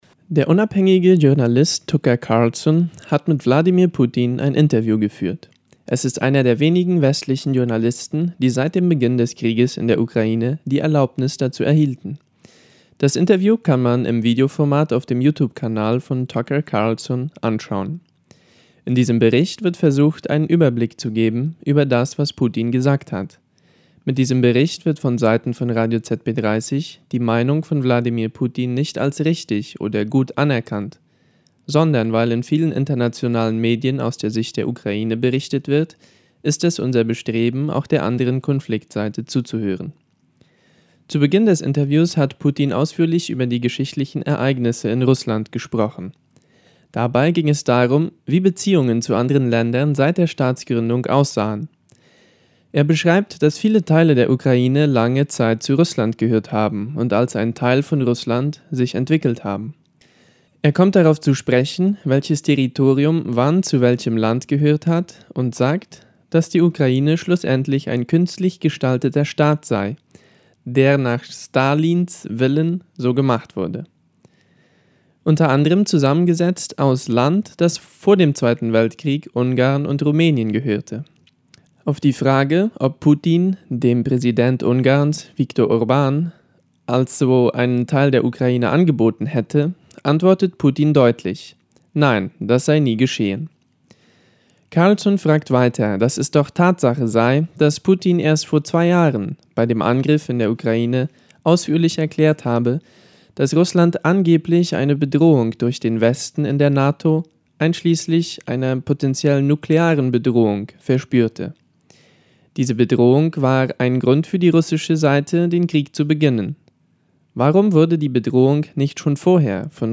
2024-02-10_Interview mit Russlands Präsidenten Putin und Tucker Carlson